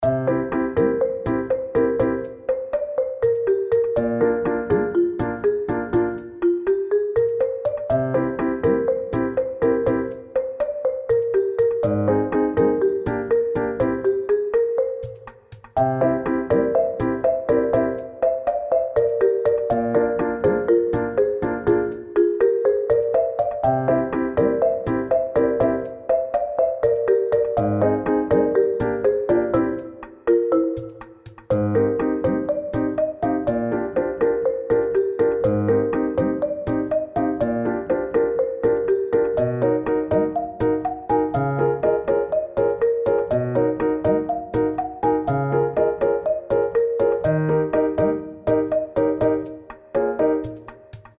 ファンタジー系フリーBGM｜ゲーム・動画・TRPGなどに！
ピアノと木琴とリコーダーでまったりゆるめな曲です。